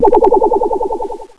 generator_on.wav